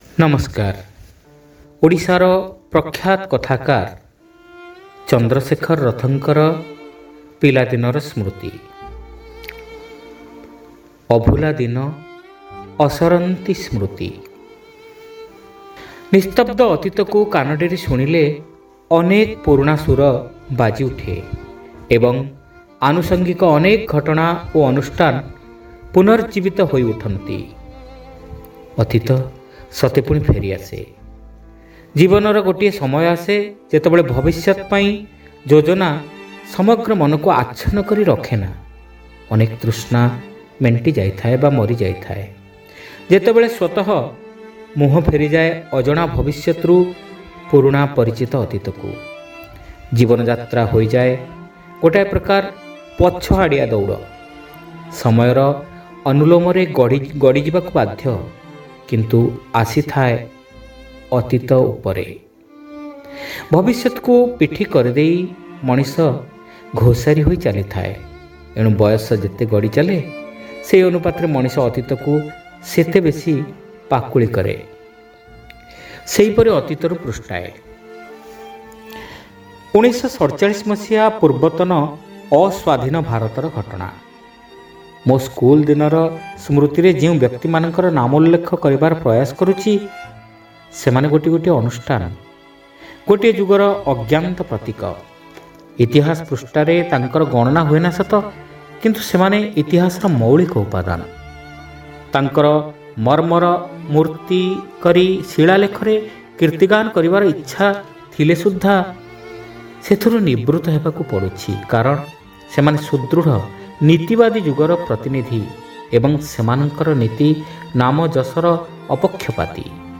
ଶ୍ରାବ୍ୟ ଗଳ୍ପ : ଅଭୁଲା ଦିନ ଅସରନ୍ତି ସ୍ମୃତି